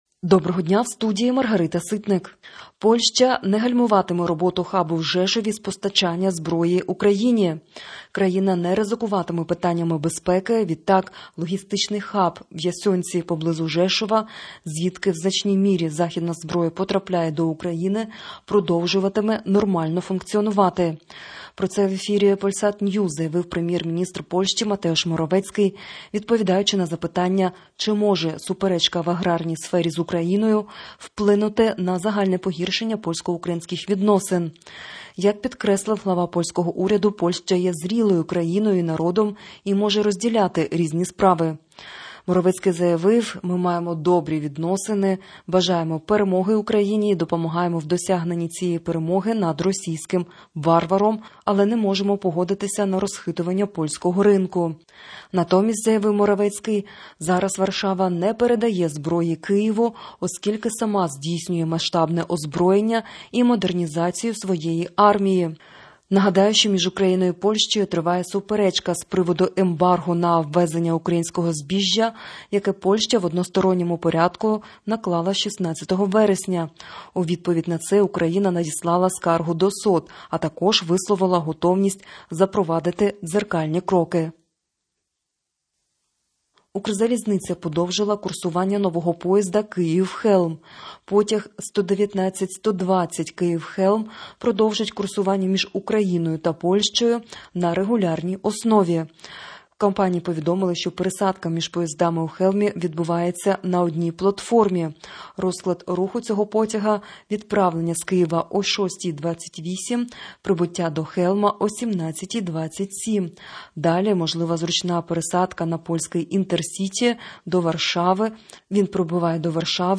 Ukrainian-Morning-News-21.09.mp3